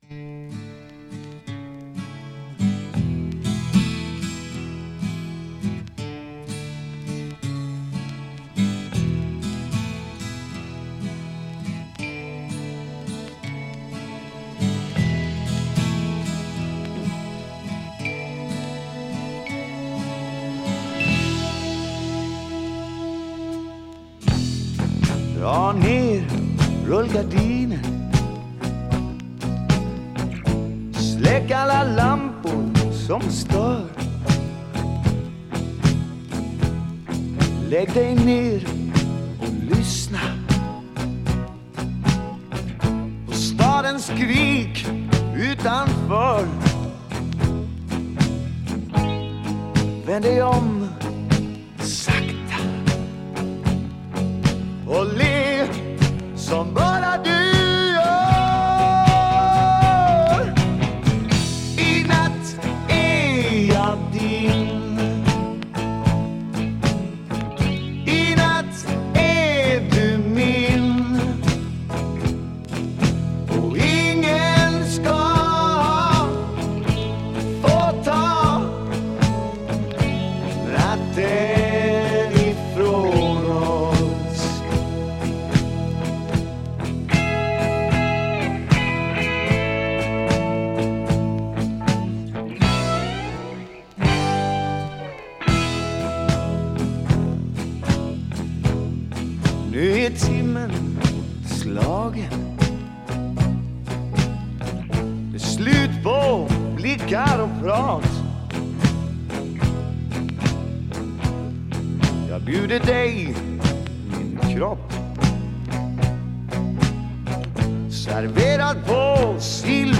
Vocals, Piano, Fender Rhodes 88,
Bass
Vocals, Drums, Percussion
Vocals, Guitars, Mandolin